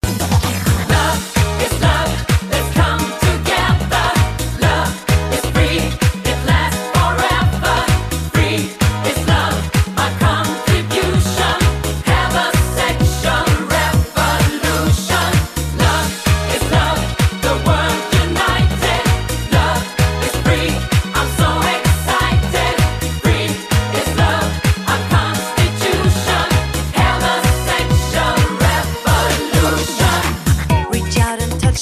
• Качество: 174, Stereo
мужской вокал
женский вокал
ретро